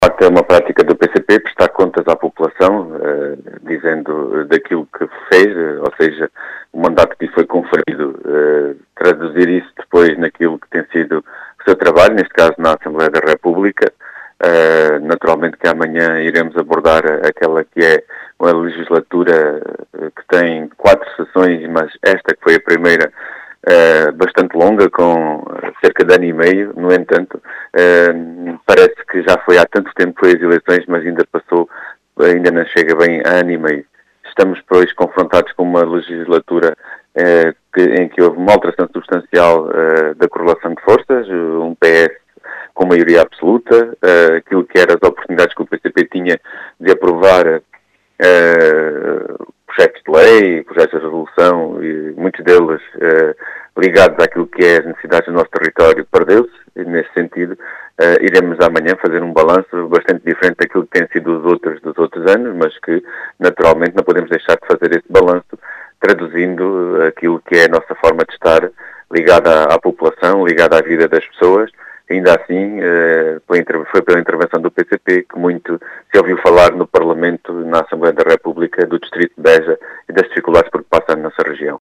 Em declarações à Rádio Vidigueira, João Dias, deputado do PCP eleito por Beja, diz ser uma “prática do PCP, prestar contas à população”.